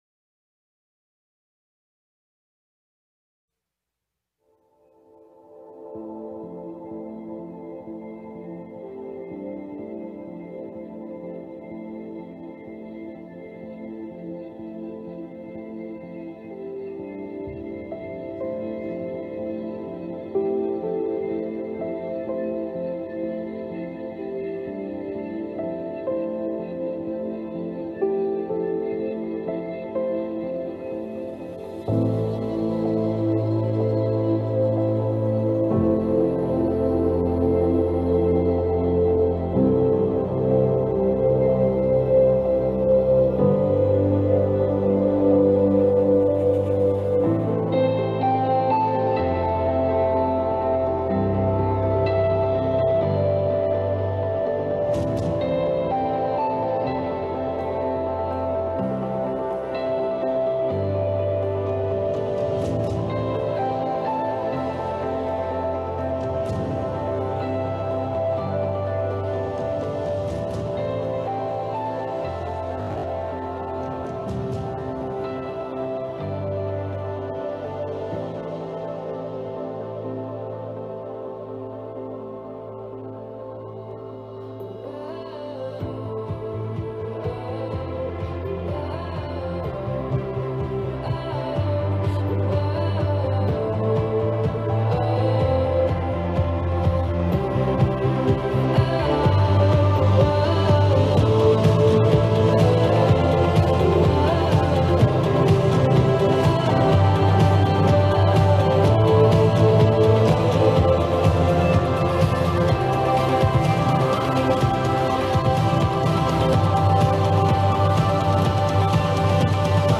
Sermons – Desert Hope Lutheran Church